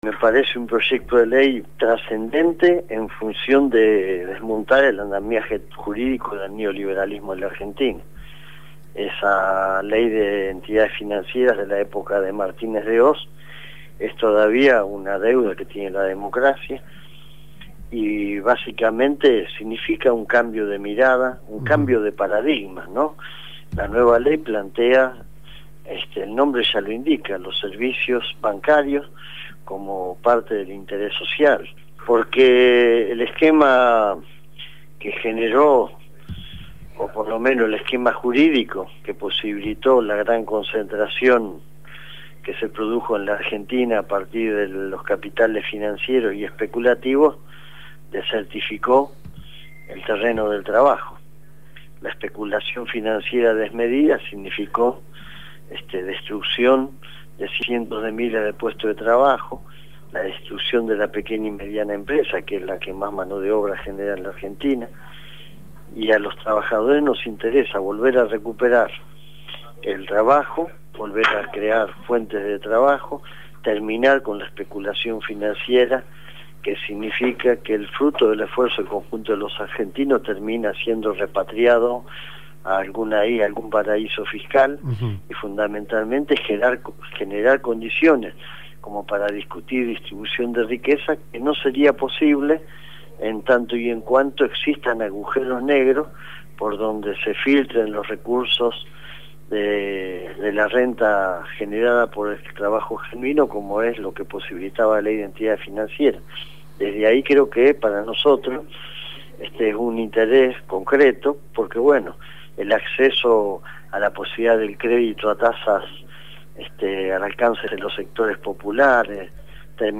El Secretario General de la CTA fue entrevistado